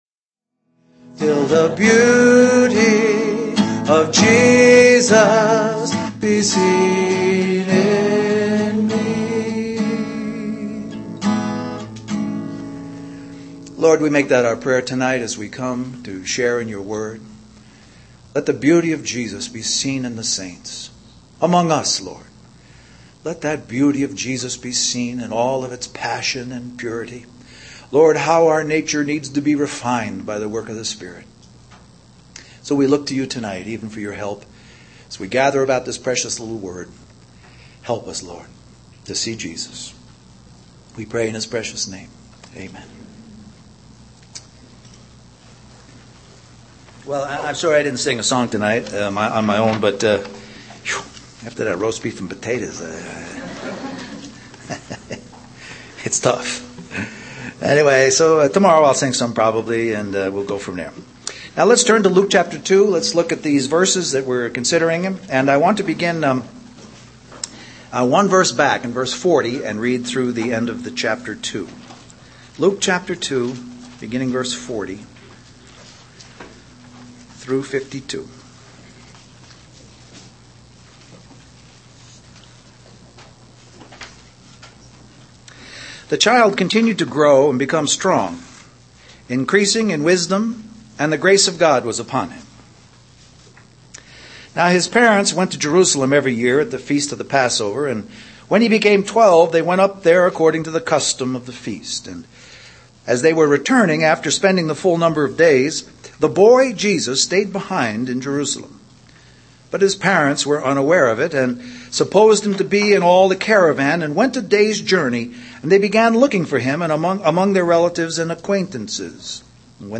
A collection of Christ focused messages published by the Christian Testimony Ministry in Richmond, VA.
Toronto Summer Youth Conference